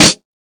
• 00s Crispy Snare Drum Sample C# Key 04.wav
Royality free snare sound tuned to the C# note. Loudest frequency: 3839Hz
00s-crispy-snare-drum-sample-c-sharp-key-04-7yp.wav